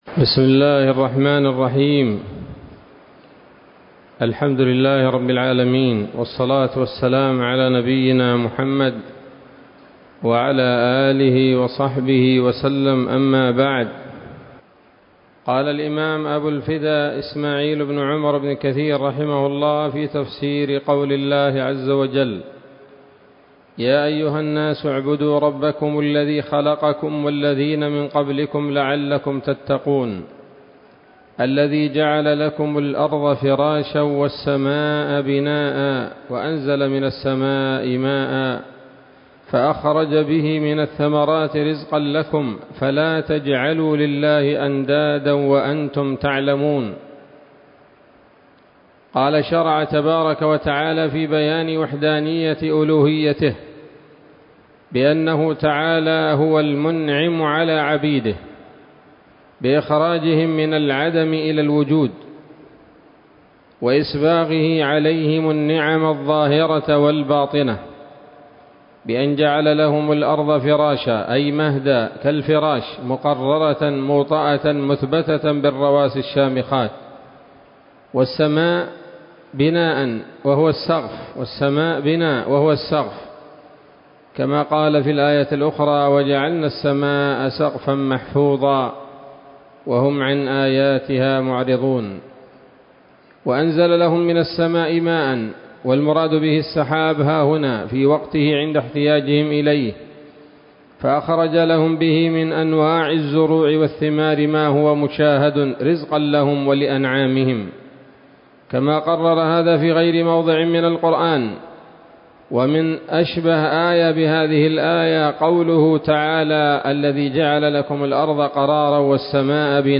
الدرس الثالث والعشرون من سورة البقرة من تفسير ابن كثير رحمه الله تعالى